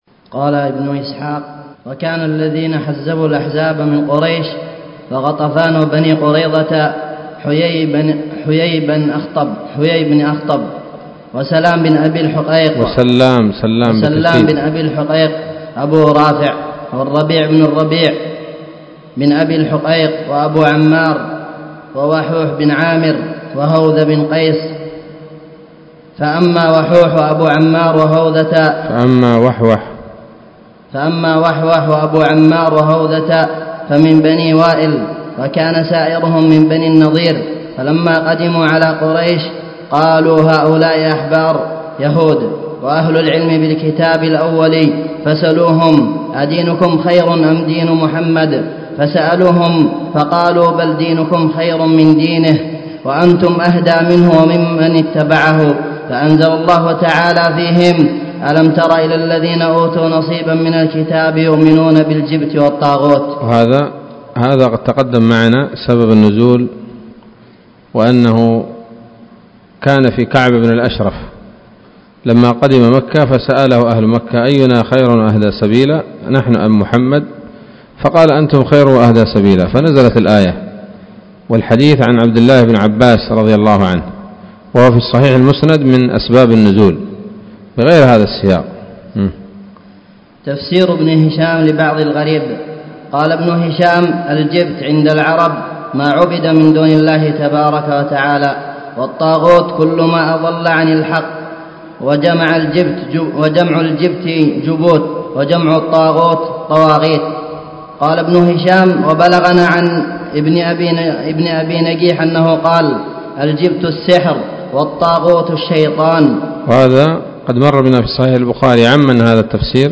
الدرس السادس والتسعون من التعليق على كتاب السيرة النبوية لابن هشام